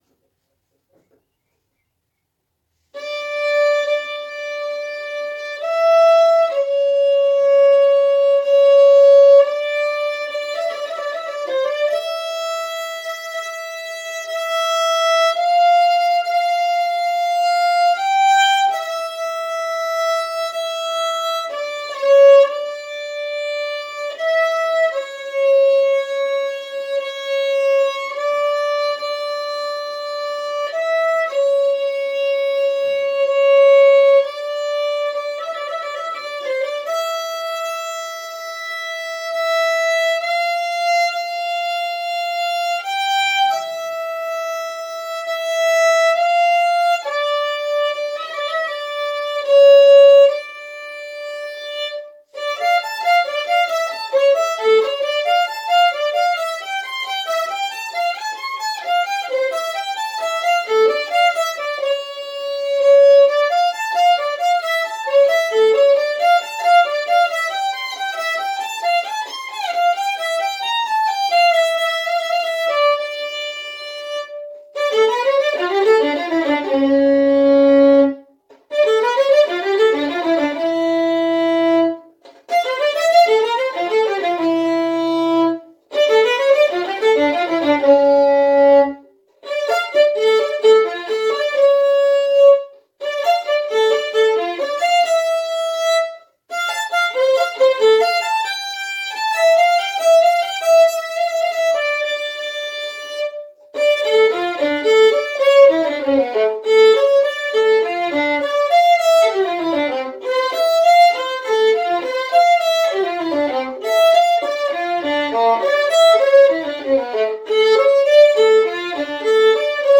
qua, eseguita apposta per me al violino dalla figlia di una genzanese in Toscana.